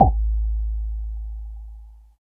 99 MOD HAT.wav